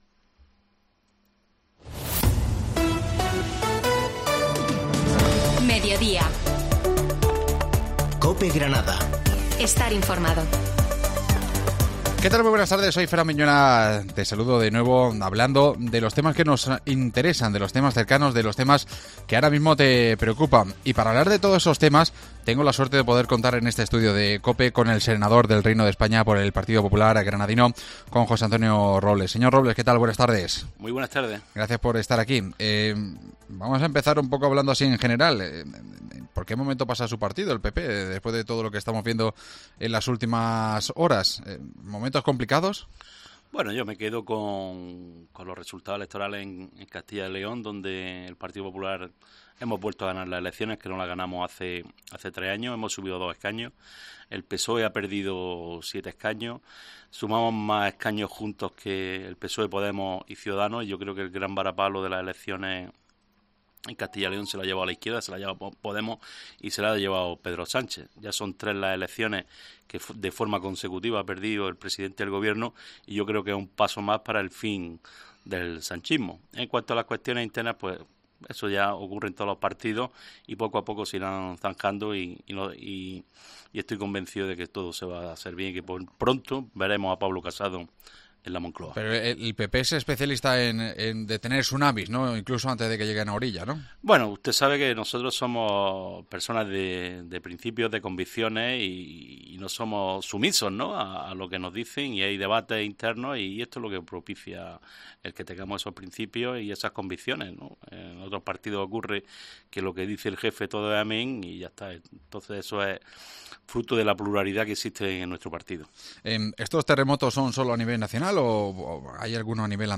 AUDIO: El senador del PP granadino, José Robles, repasa en COPE la actualidad política